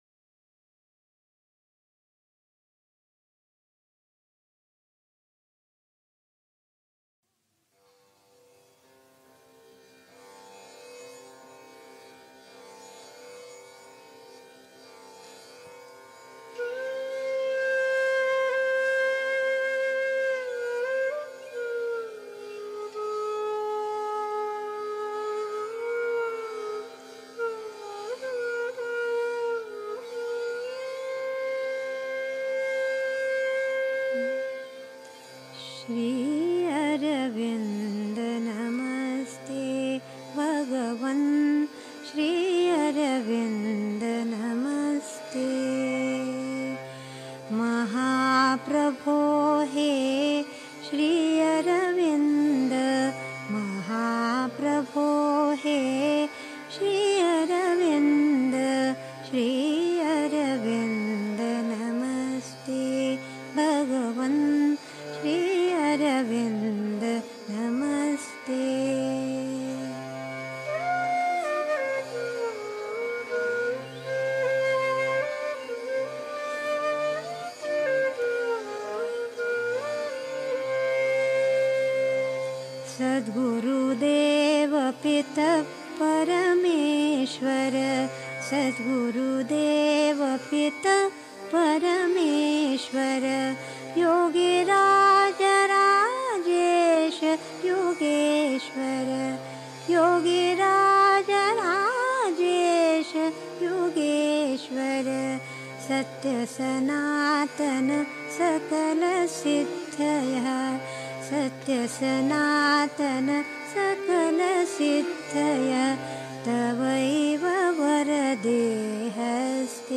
1. Einstimmung mit Musik. 2. Der Mensch kann aus eigener Anstrengung nichts aus sich machen, was mehr ist als ein Mensch (Sri Aurobindo, CWSA, Vol. 12, pp. 169-71) 3. Zwölf Minuten Stille.